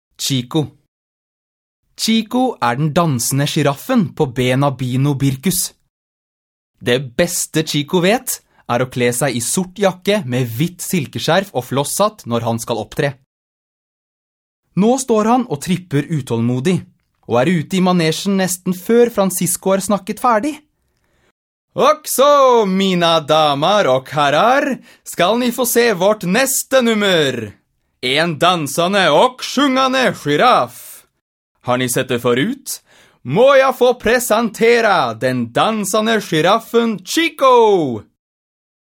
Lesetrening - Sjiraffen Chico